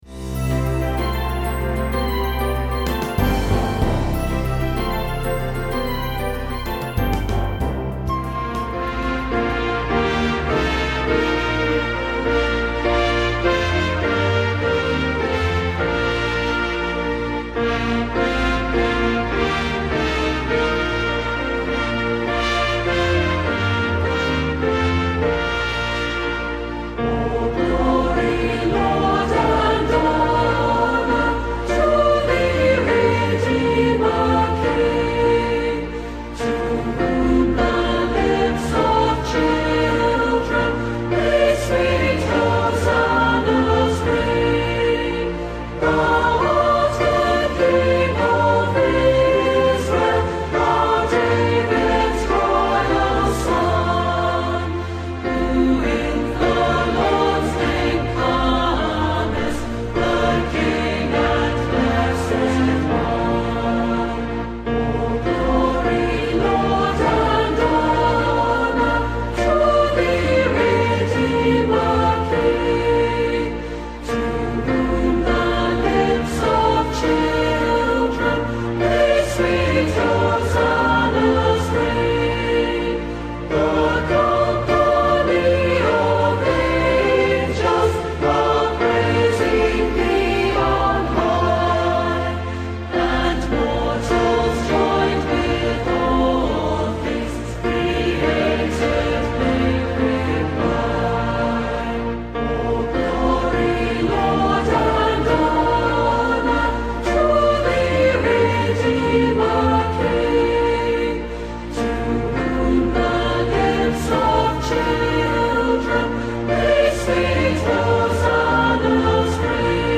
St Paul Sermon Series